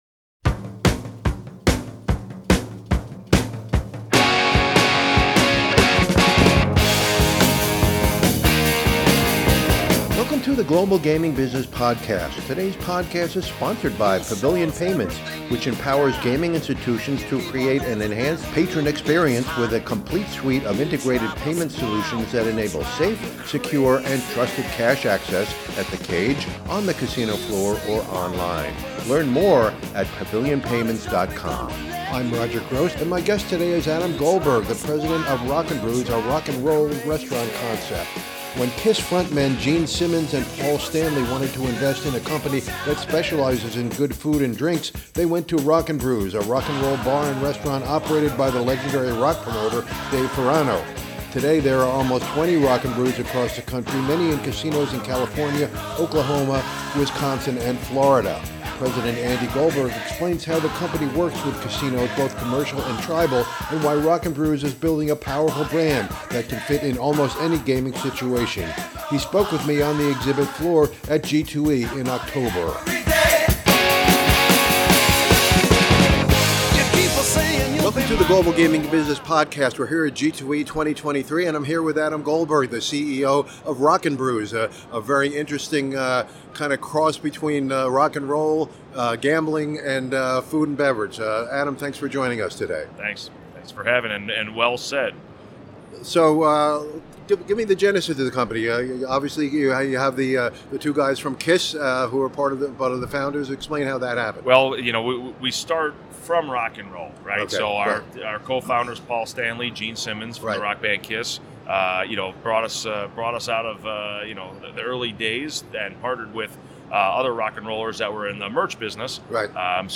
on the exhibit floor at G2E in October in Las Vegas